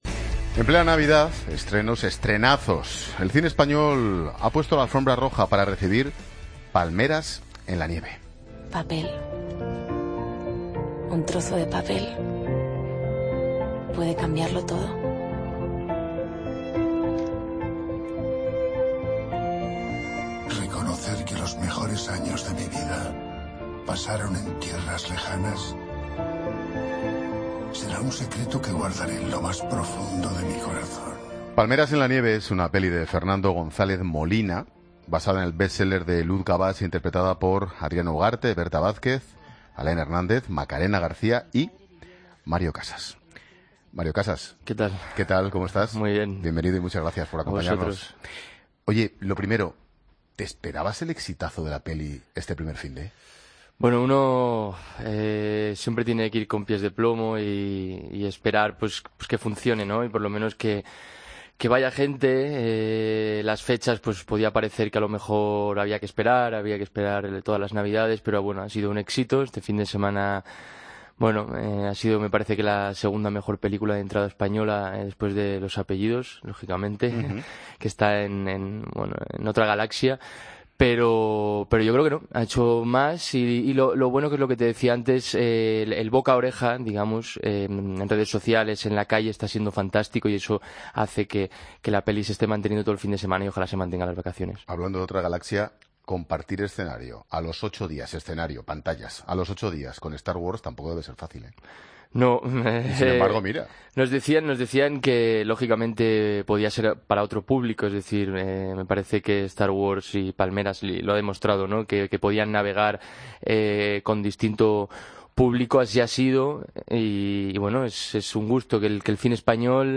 Entrevista a Mario Casas en La Tarde